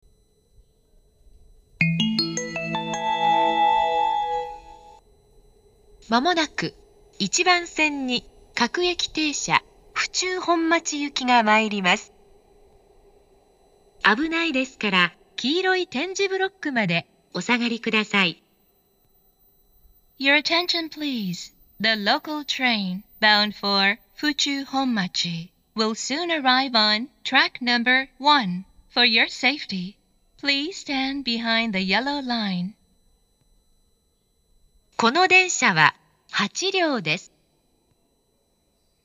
１番線接近放送
hatchobori1bansen-sekkin3.mp3